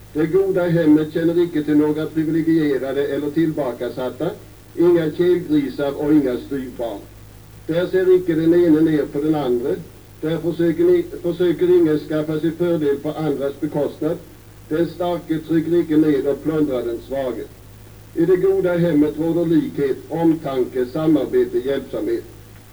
Folkhemstalet hölls inför andra kammarens ledamöter i riksdagen 1928.
Talet sändes även i radio.
Om du klickar på ikonen härunder laddar du hem en wavefil där du hör Per Albin Hanssons röst och ett utdrag ur Folkhemstalet